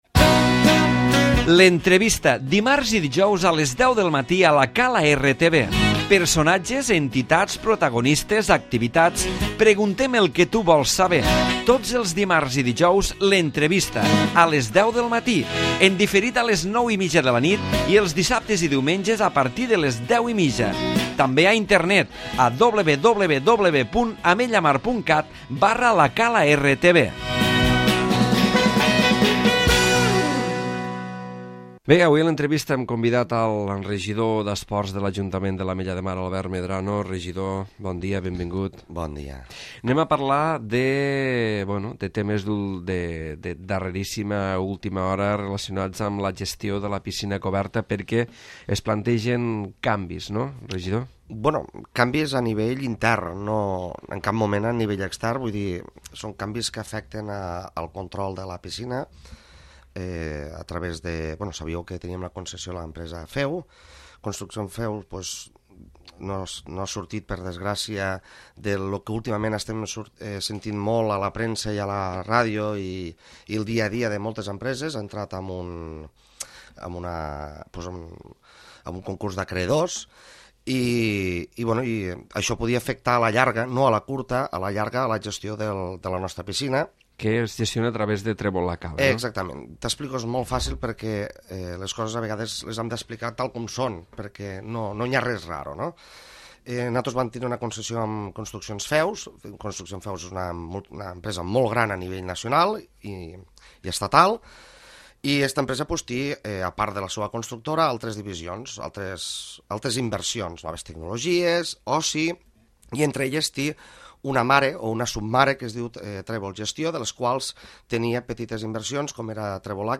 L'Entrevista
Albert Medrano, regidor d'Esports de l'Ajuntament de l'Ametlla de Mar parla a l'Entrevista dels canvis en la gestió del Complex esportiu de la Piscina Municipal produïts amb l'adquisició per part de La Cala Gestió del capital de Trèvol La Cala, acció